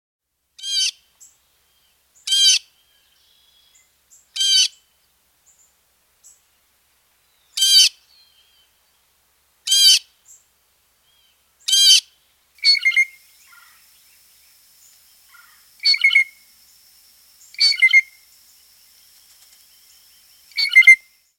Blue Jay
Bird Sound
Blue Jays make a large variety of calls. The most often heard is a loud jeer, Also makes clear whistled notes and gurgling sounds. Blue Jays frequently mimic hawks, especially Red-shouldered Hawks.
BlueJays.mp3